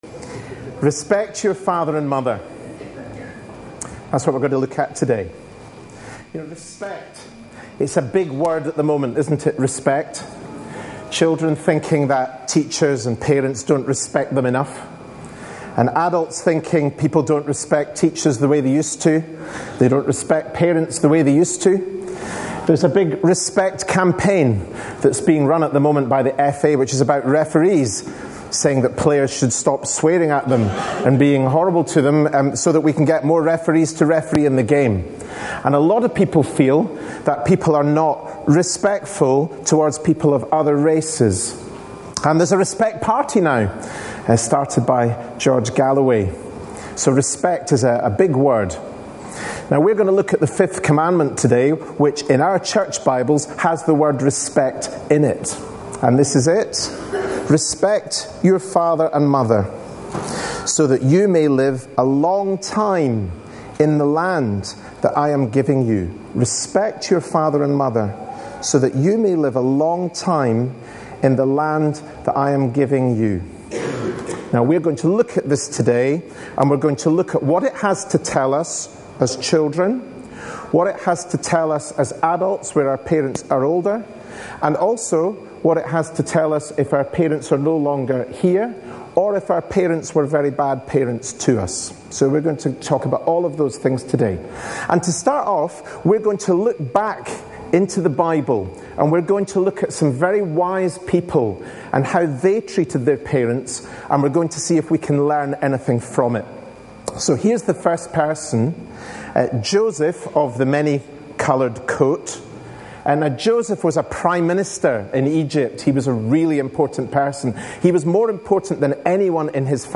A sermon preached on 21st October, 2012, as part of our Family Service - The Ten Commandments series.